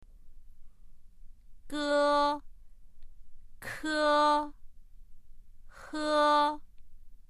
（　）の母音をつけた第一声の発音を聞いてみましょう。
ge-ke-he.mp3